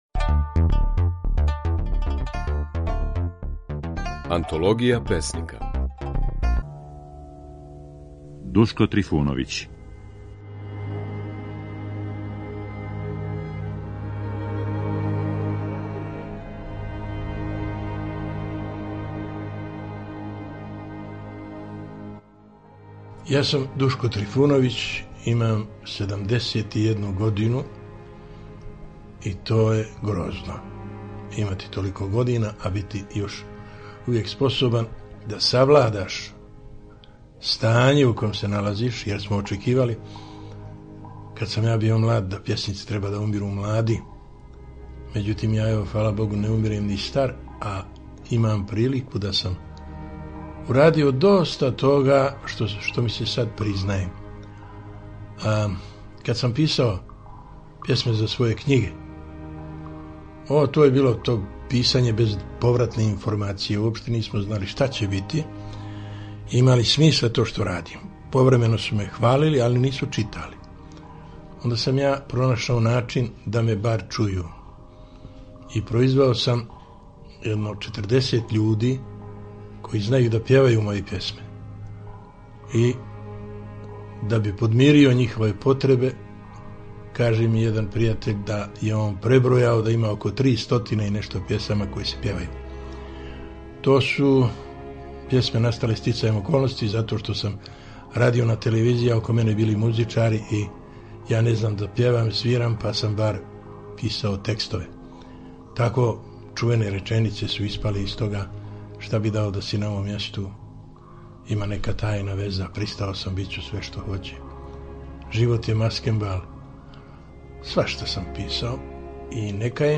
У емисији Aнтологија песника, слушаћемо како је своје стихове говорио песник Душко Трифуновић (1933–2006).